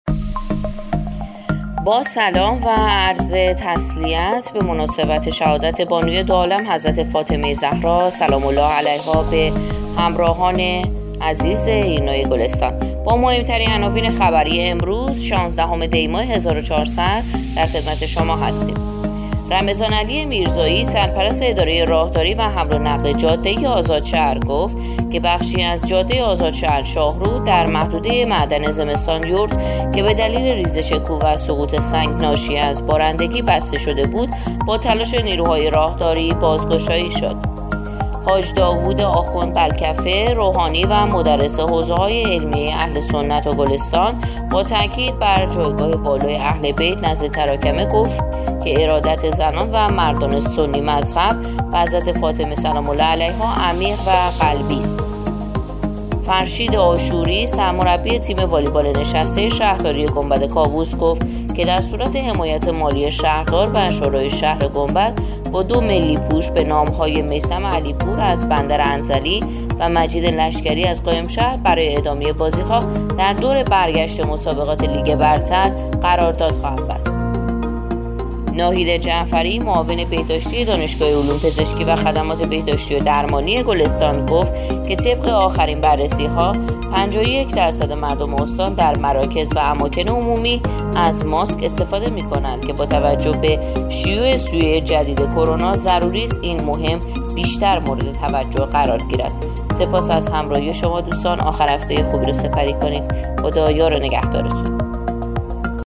پادکست/ اخبار شامگاهی شانزدهم دی ماه ایرنا گلستان